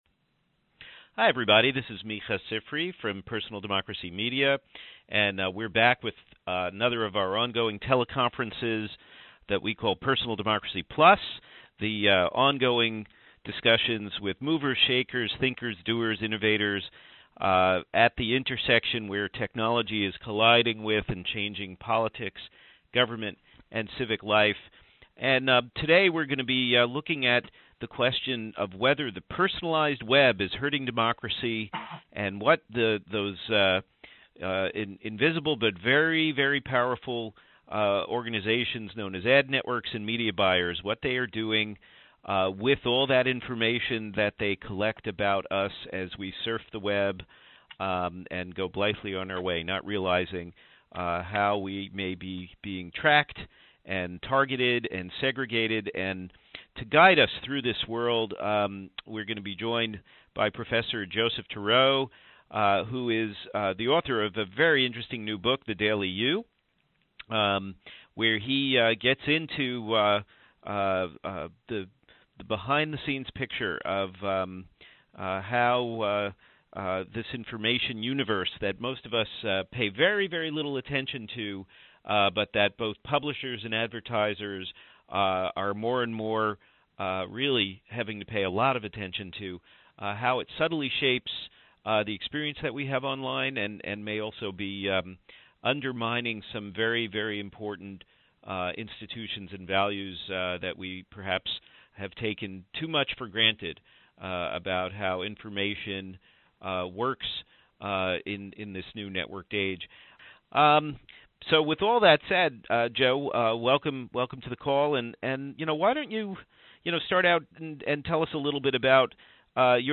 On this PD+ call, we'll ask, what is the online advertising business doing to democracy?